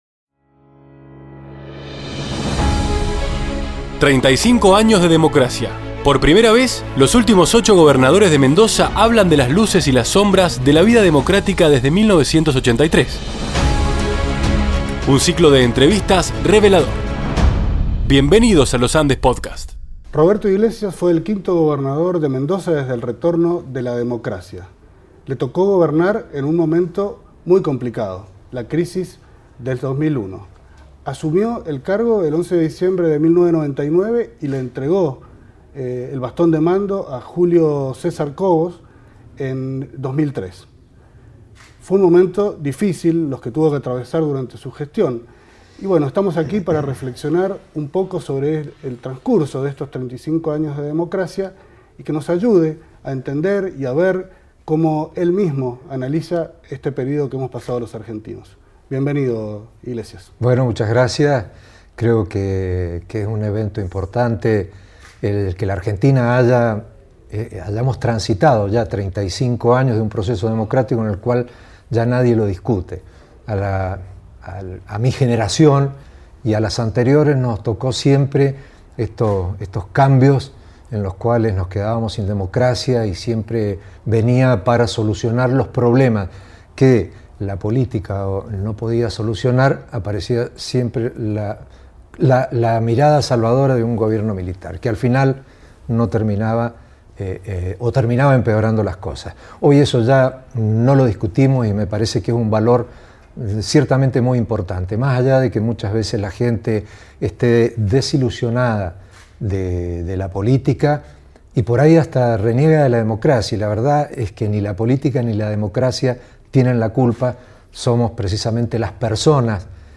35 AÑOS DE DEMOCRACIA: entrevista a Roberto Iglesias